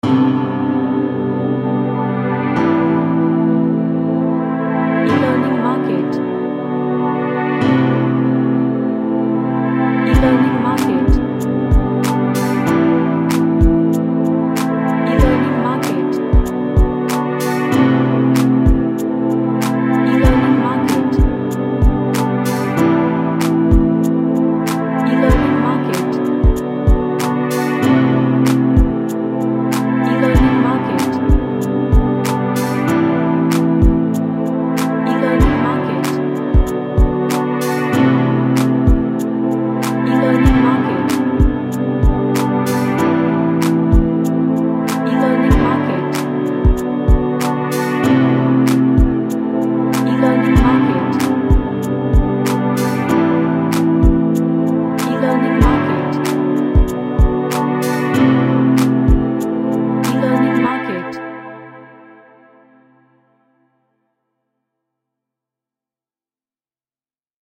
An Ambient track with lots of Pads and Ambience.
Happy